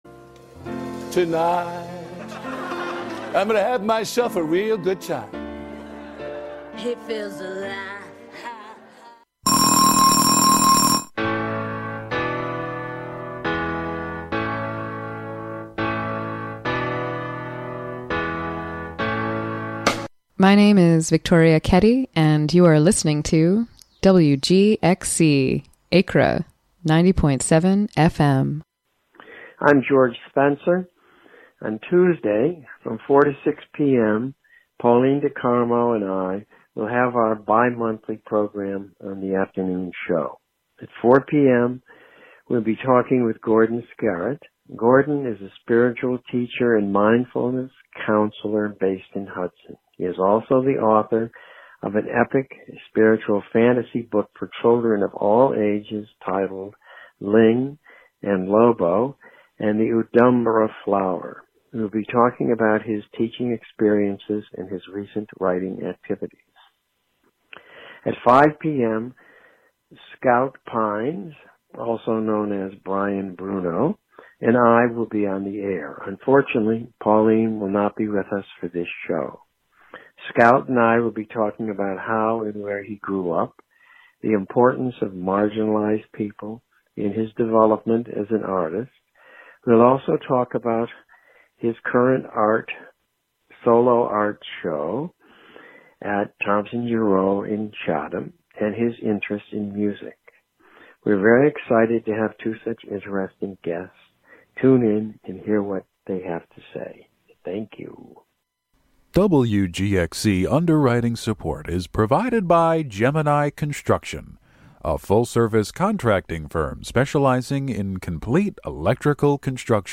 This particular transmission will include "noise" recordings from around South East Asia, Tokyo, Korea and Mexico inter-cut with interviews about sound and noise. Presented in the style of Tokyo's famous listening cafes.